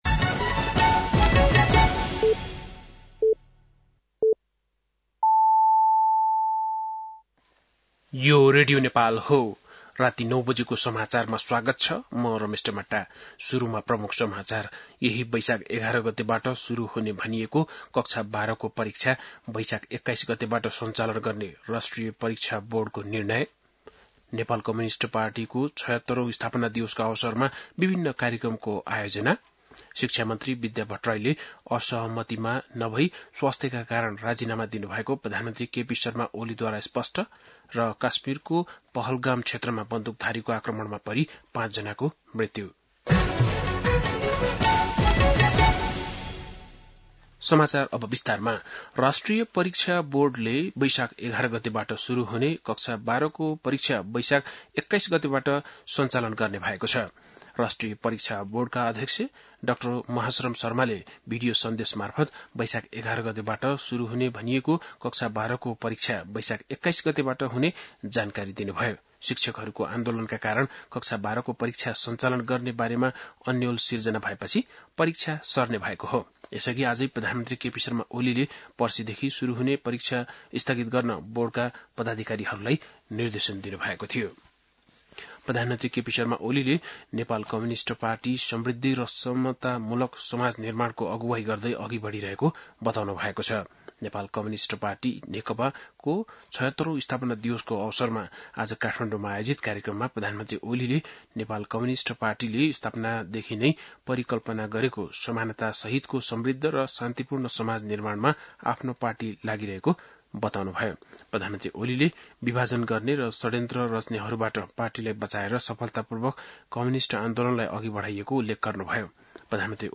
बेलुकी ९ बजेको नेपाली समाचार : ९ वैशाख , २०८२
9-pm-nepali-news-4.mp3